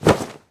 Pillow_drop_02.ogg